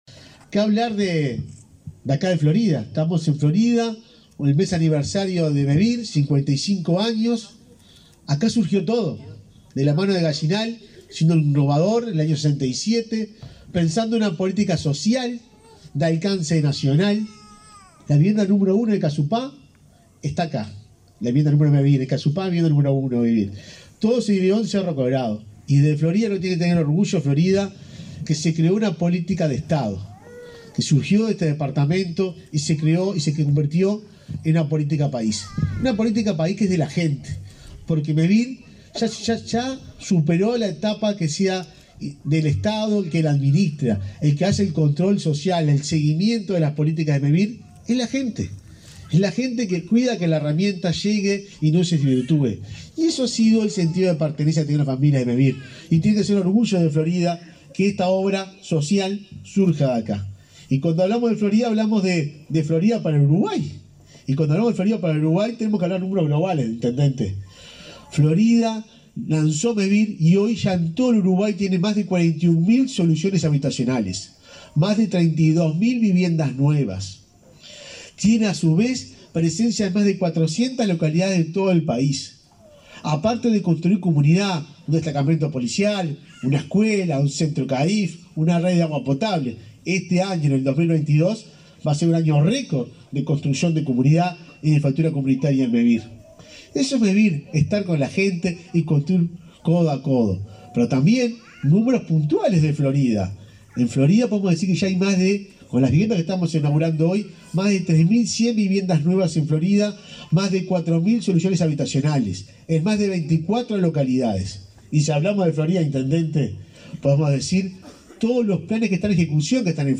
Palabras de autoridades en inauguración de Mevir en Florida
El presidente de la entidad, Juan Pablo Delgado; el director nacional de Energía, Fitzgerald Cantero, y el secretario de Presidencia, Álvaro Delgado, destacaron el rol de Mevir para las poblaciones rurales.